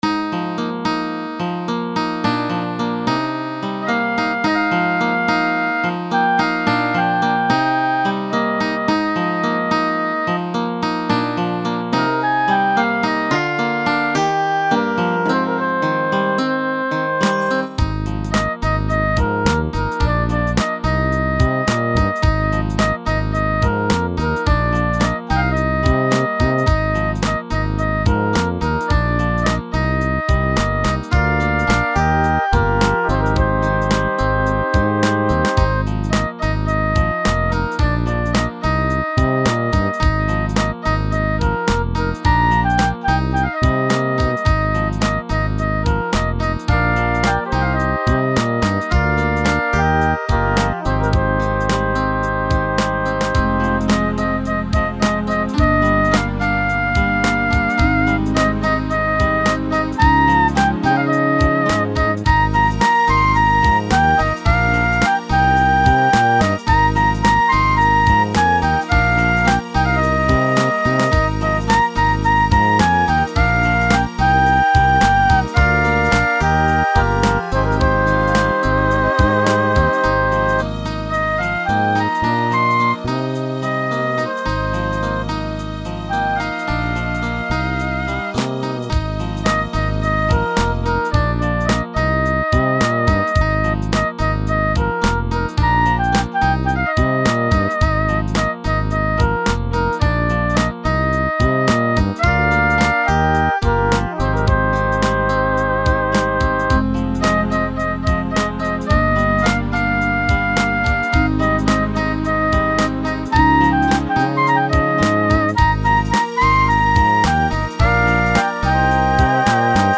Microsoft GS Wavetable Synthesis (available on all cards)